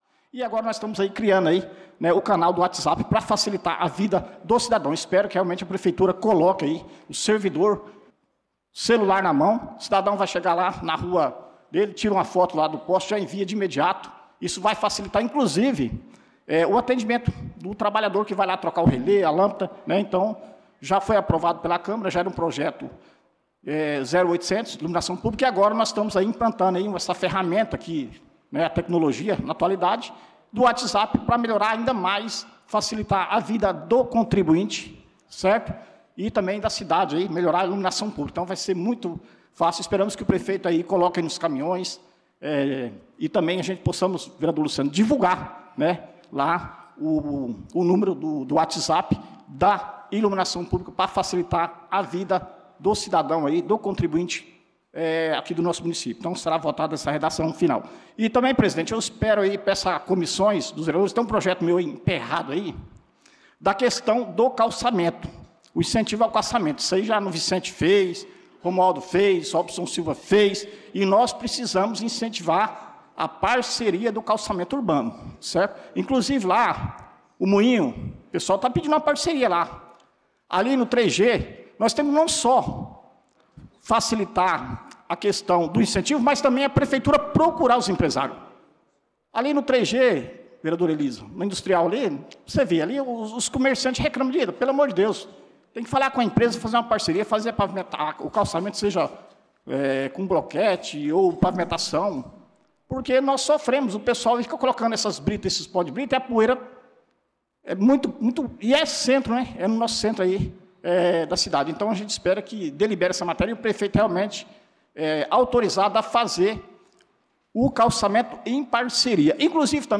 Pronunciamento do vereador Dida Pires na Sessão Ordinária do dia 11/08/2025.